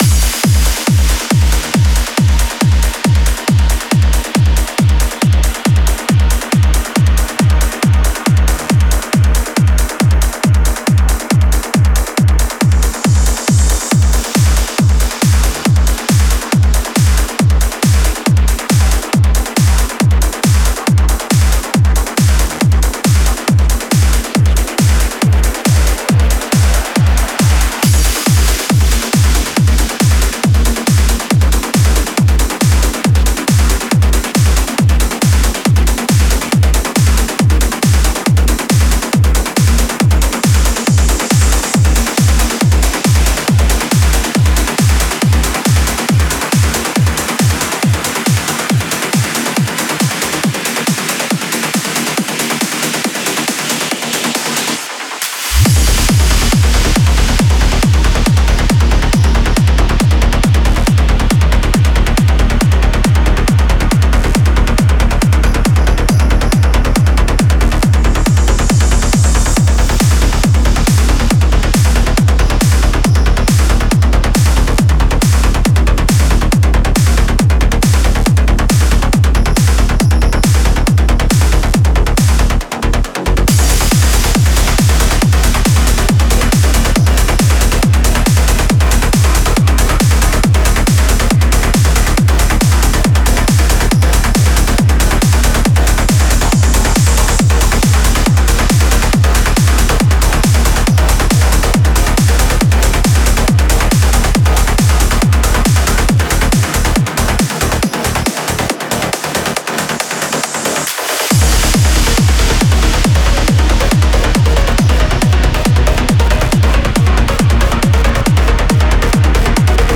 Назад в Psylifting / Tech Lifting / Trance / Tech Trance
Стиль: Trance